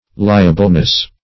Liableness \Li"a*ble*ness\, n. Quality of being liable; liability.